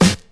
• 00's Deep Hip-Hop Snare F# Key 11.wav
Royality free snare sample tuned to the F# note. Loudest frequency: 1945Hz
00s-deep-hip-hop-snare-f-sharp-key-11-Dj1.wav